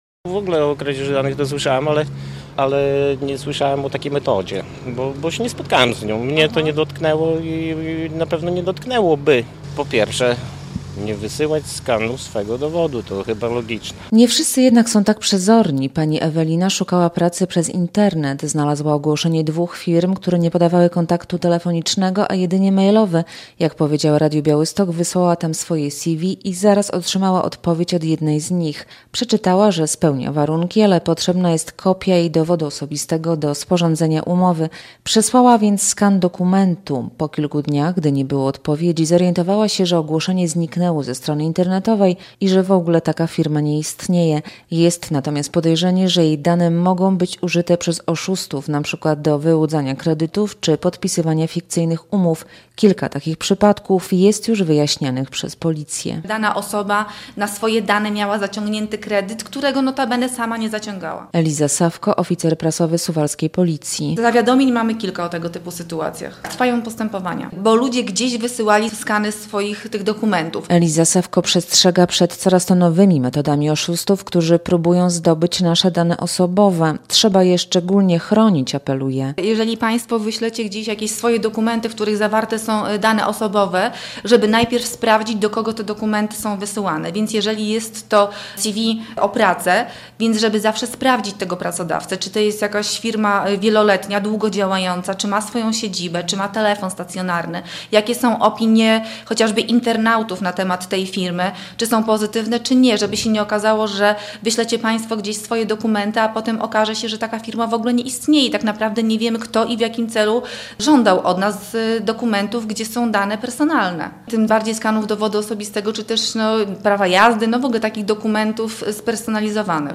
Nowe sposoby kradzieży danych osobowych - relacja